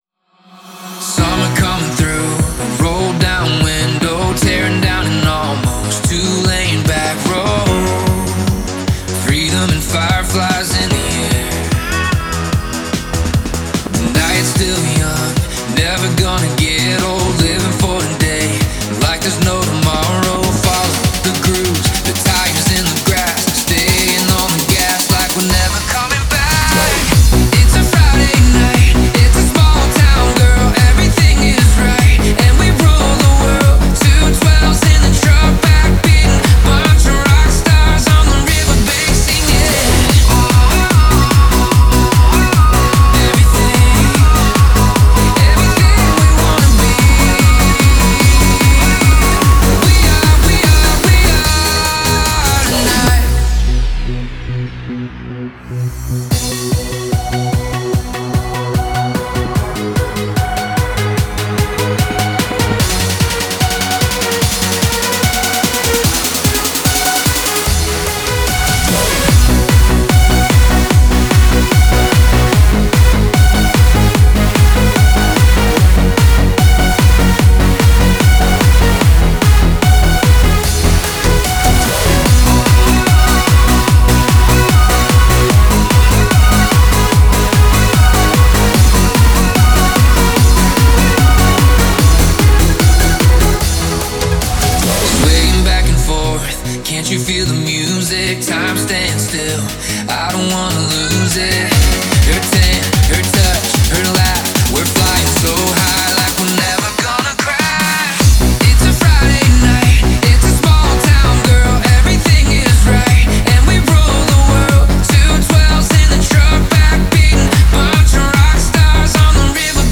это энергичный трек в жанре евродэнс